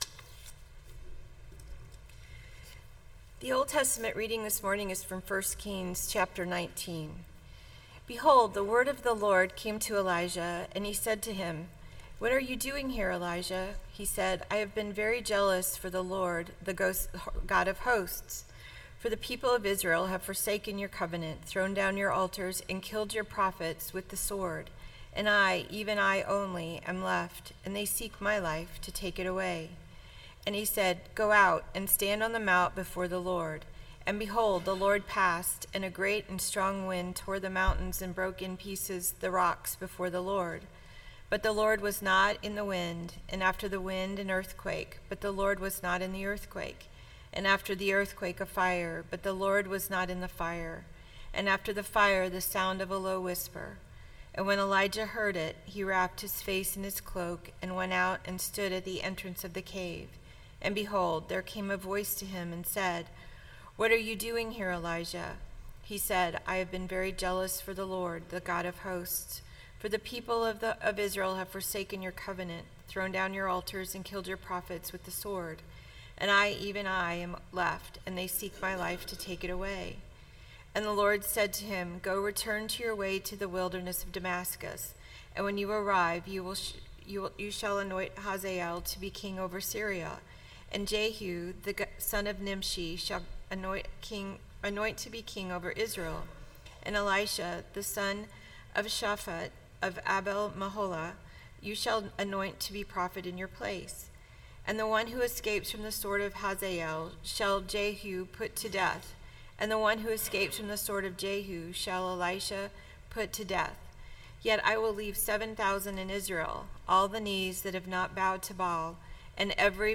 062925 Sermon Download Biblical Text: 1 Kings 19:9-21 Our lectionary tends to skip texts with a bent to violence or martial images, like Elijah and the Prophets of Baal.